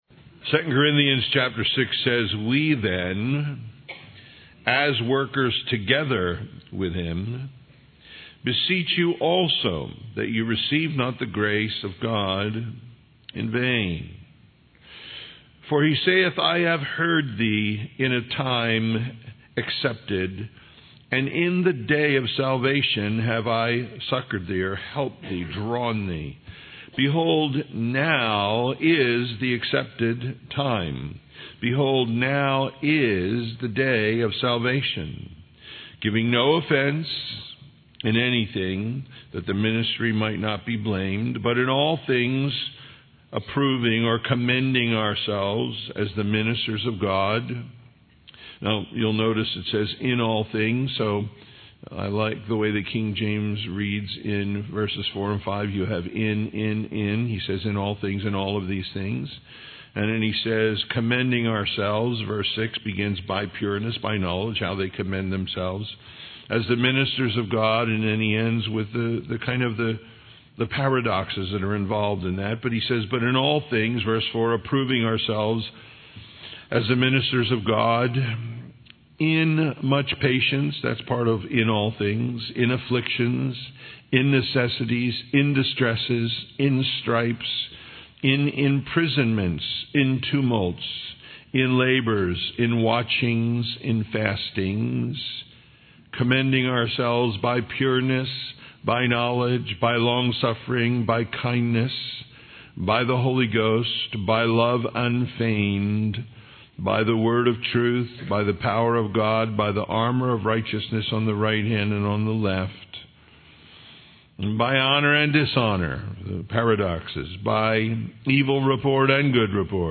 II Corinthians 6:1-6:10 What Ministry Looks Like Listen Download Original Teaching Email Feedback 6 We then, as workers together with him, beseech you also that ye receive not the grace of God in vain.